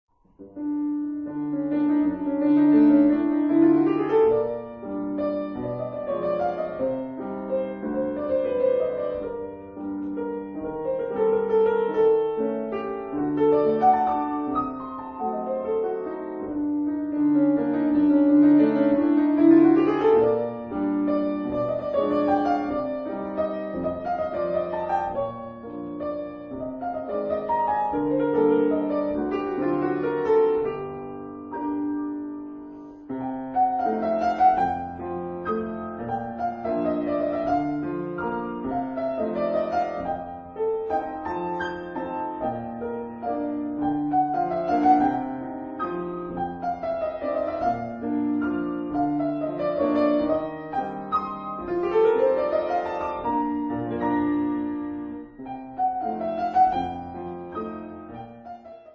Hangszer/letét:  Zongora